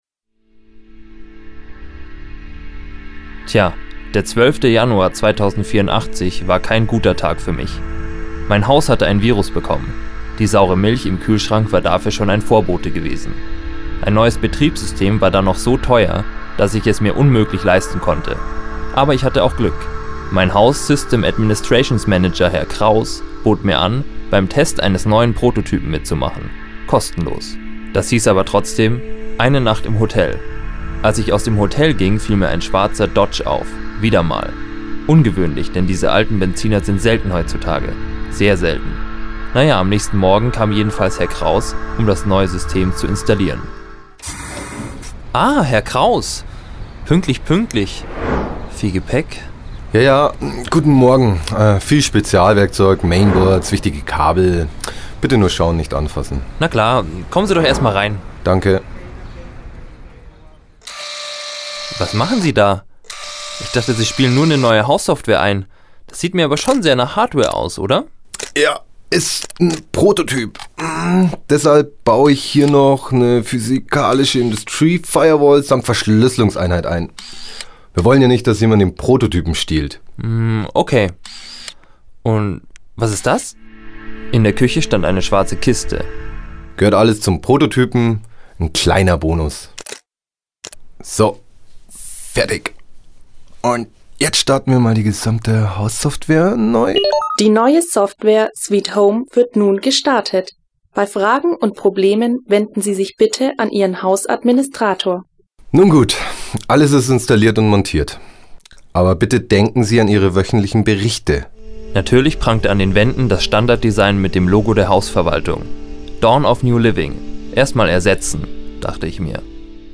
15. Hörspiel